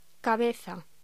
Locución